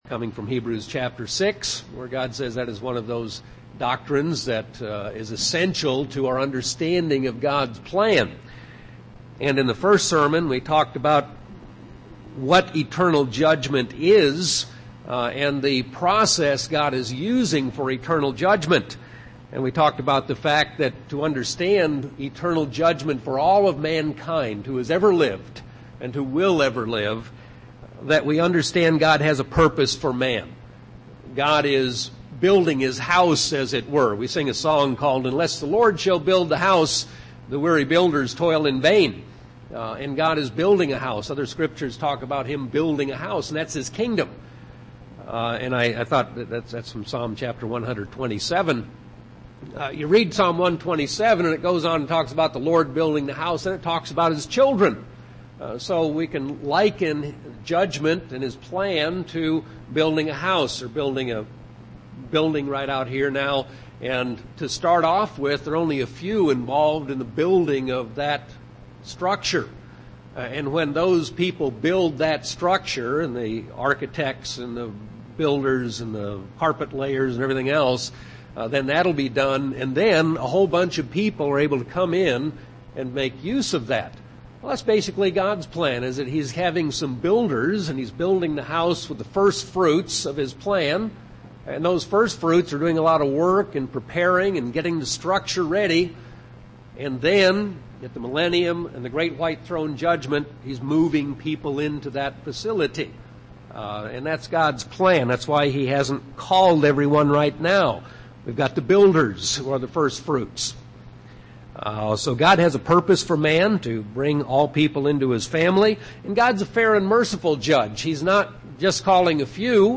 Given in Phoenix Northwest, AZ
UCG Sermon Studying the bible?